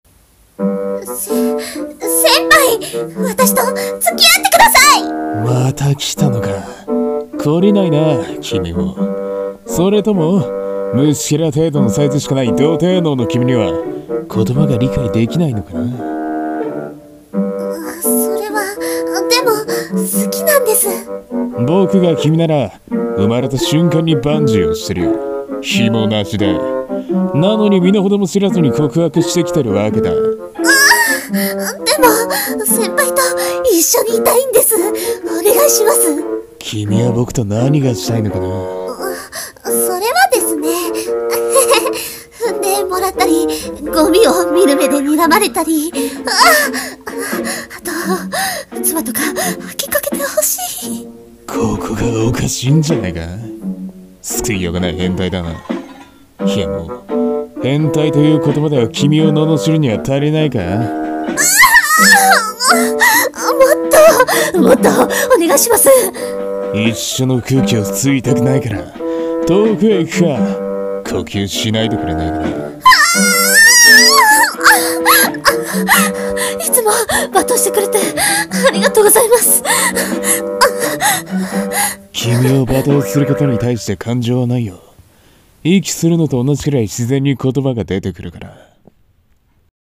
【声劇】付き合ってください（罵ってください）